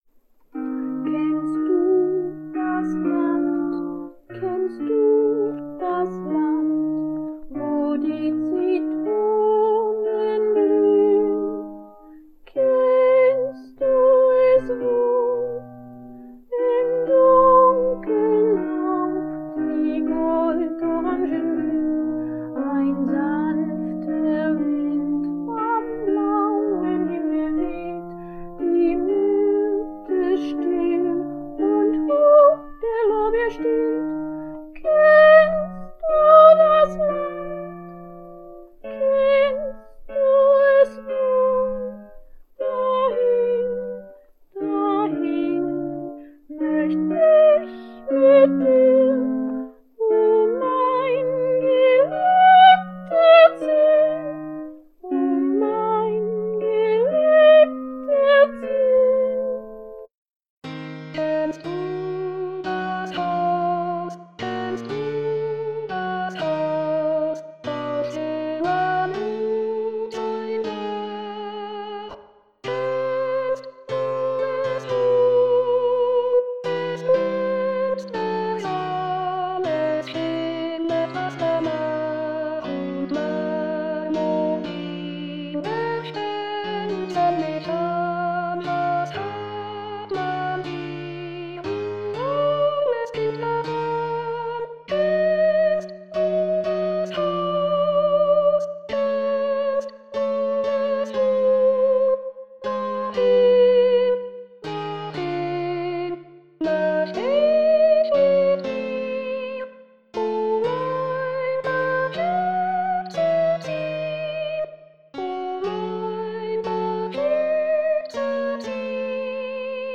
2. und 3. Strophe von Virtual Singer